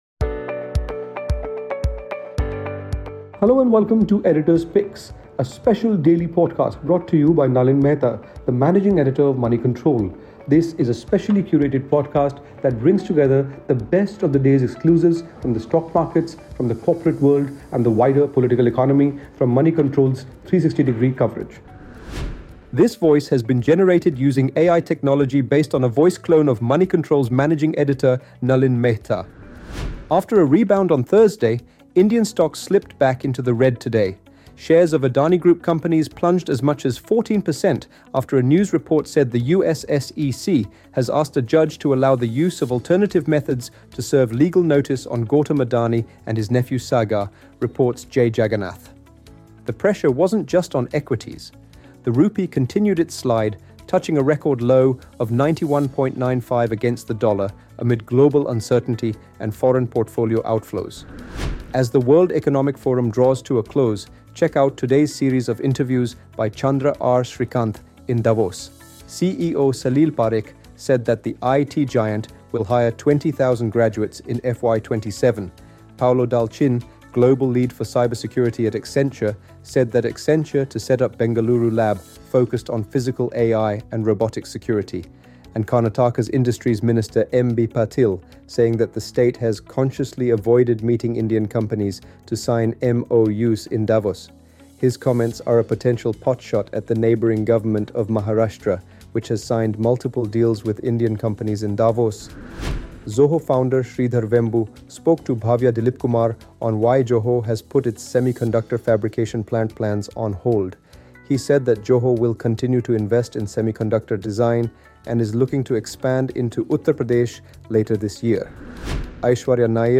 In other news - the pressure continued on Indian stocks and the Rupee, local family run jewellery stores are feeling a liquidity squeeze, learn about the sweat and blood that went into making of the India-EU trade deal and how Ixigo was an unlikely gainer from IndiGo’s crisis. Also inside: an interview with Zoho’s Sridhar Vembu.